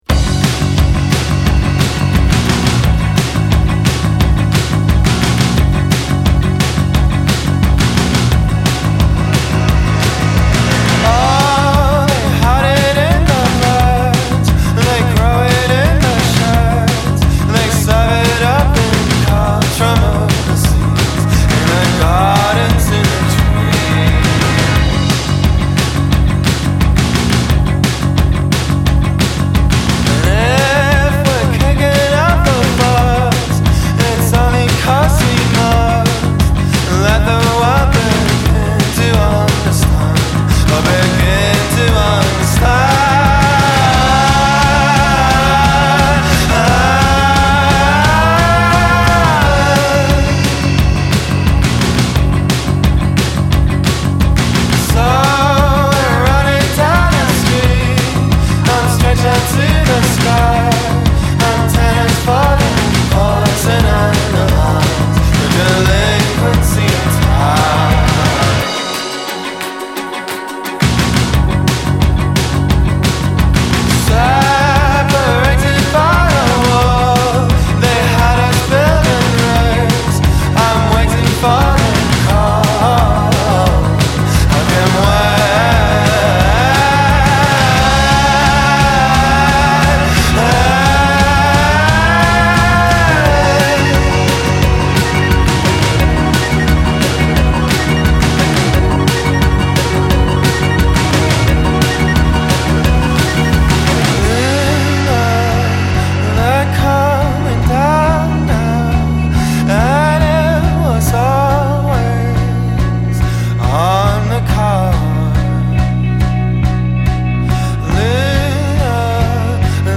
Swooping and melodic